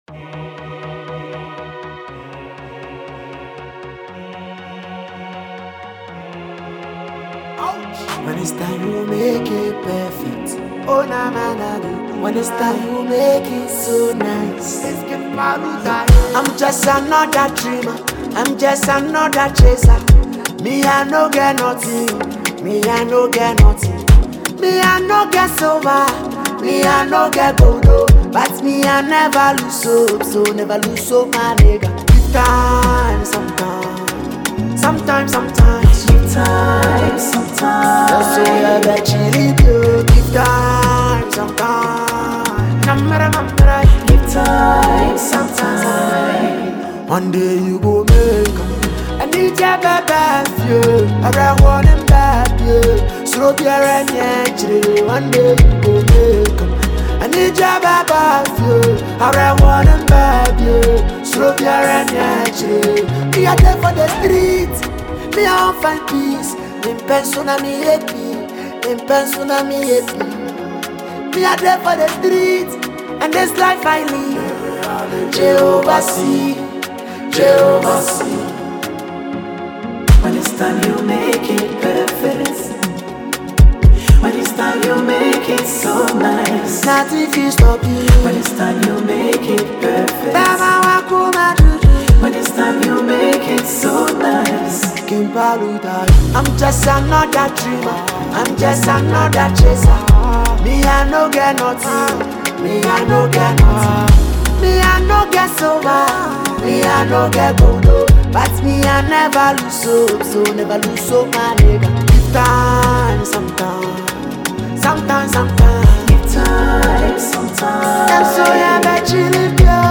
a versatile singer and rapper
a solo track